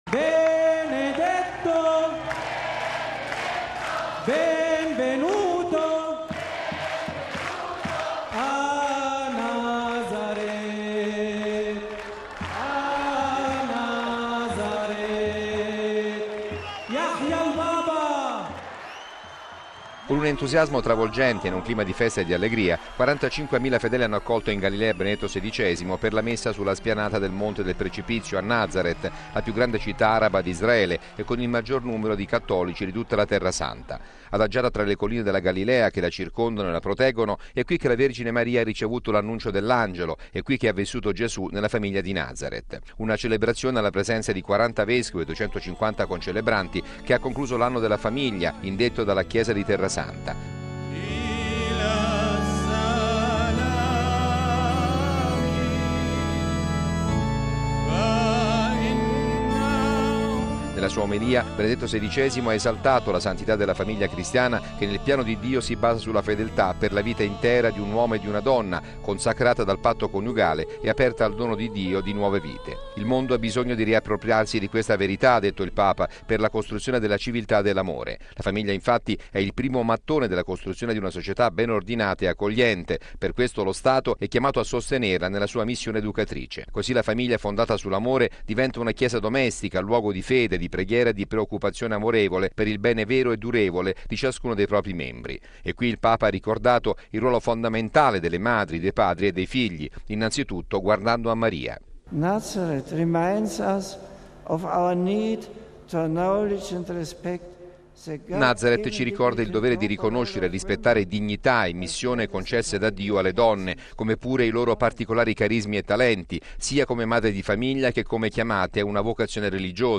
(Canti ed effetto folla)
(Parole in arabo)
(Canto)